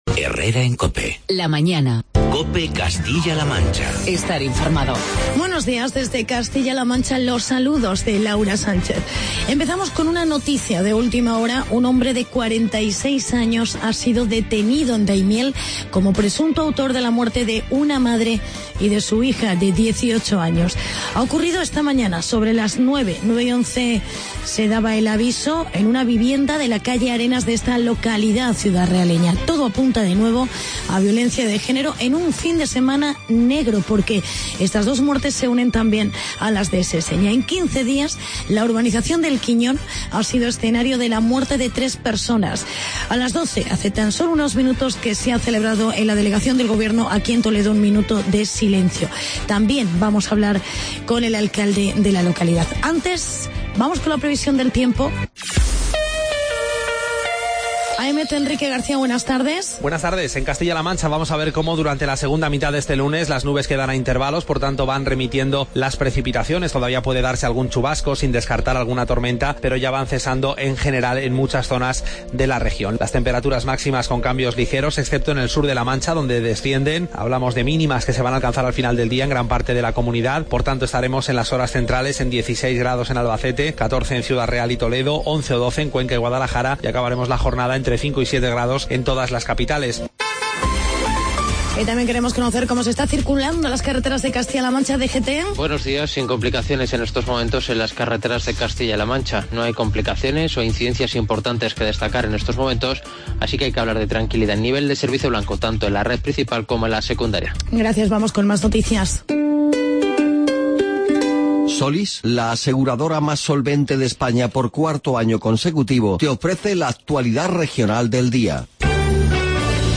Actualidad y entrevista con el alcalde de Seseña, Carlos Velázquez por las últimas víctimas de violencia de género.